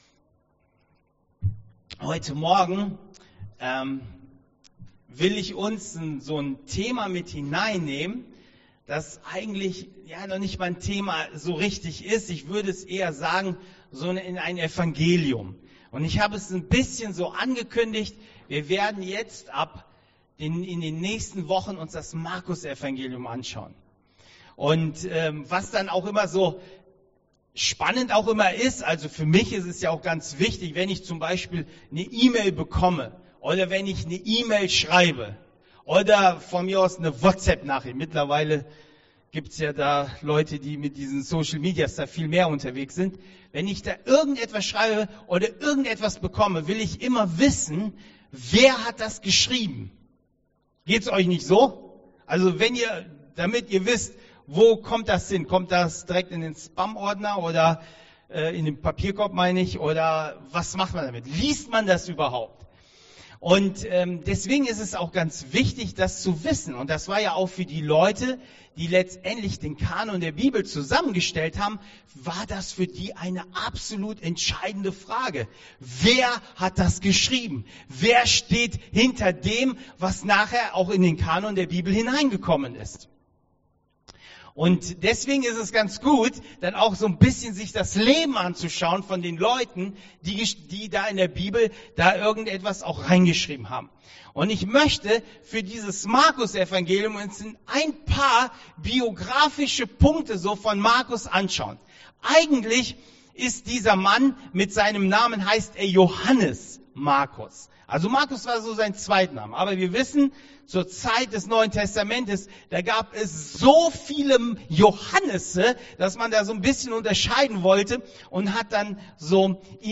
Predigt 11.04.2021